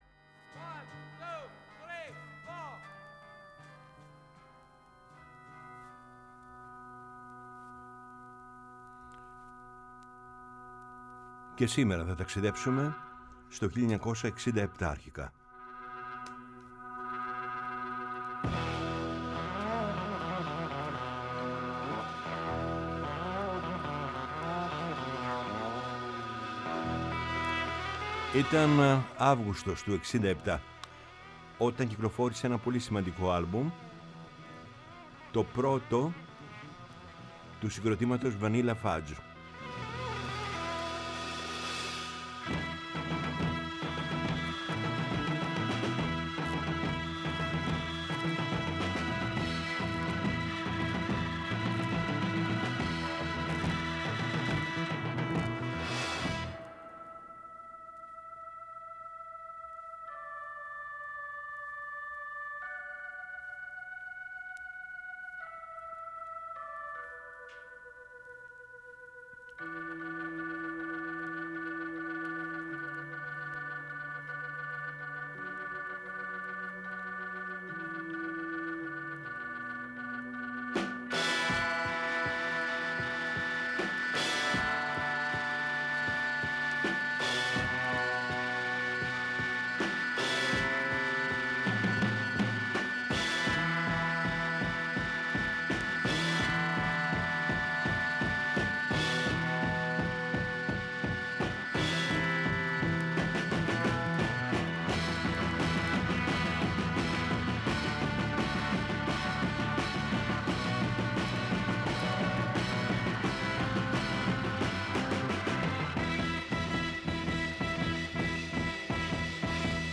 Από το 1975 ως τον Ιούνιο 2013 και από το 2017 ως σήμερα, ο Γιάννης Πετρίδης βρίσκεται στις συχνότητες της Ελληνικής Ραδιοφωνίας, καθημερινά “Από τις 4 στις 5” το απόγευμα στο Πρώτο Πρόγραμμα